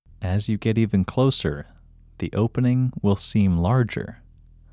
IN – Second Way – English Male 11